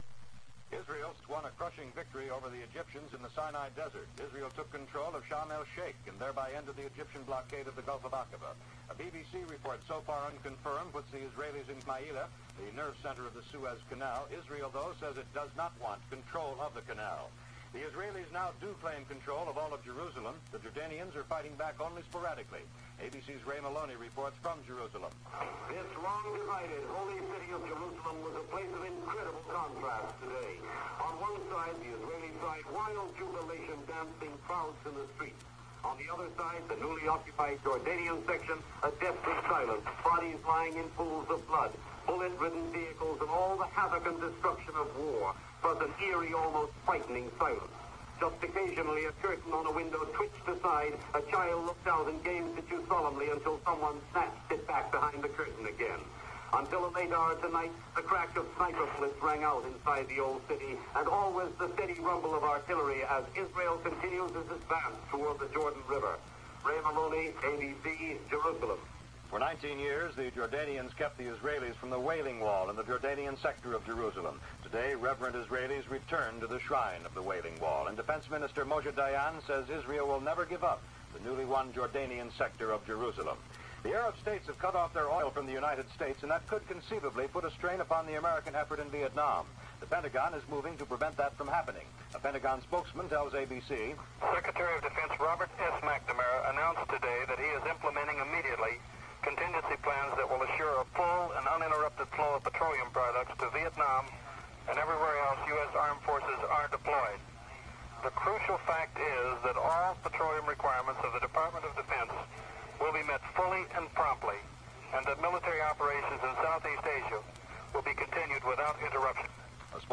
In the summer of 1967 I was recording music from WLS radio in Chicago.